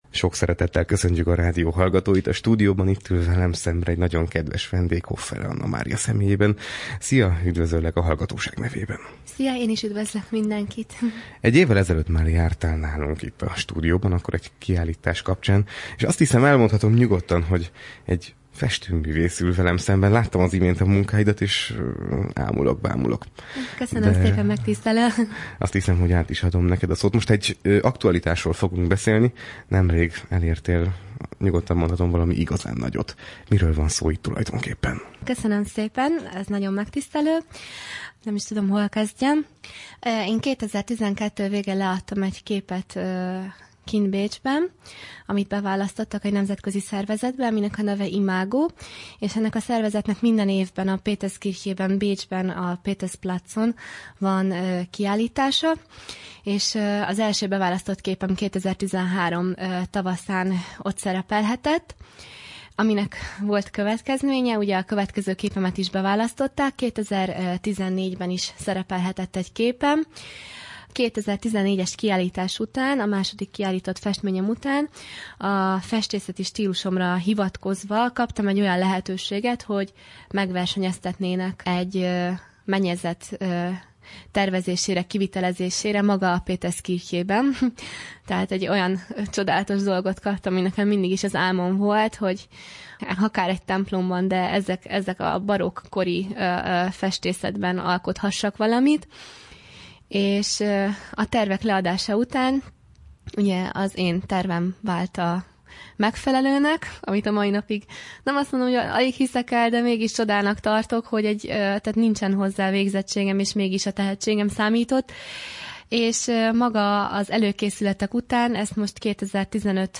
Corvinus Rádió riport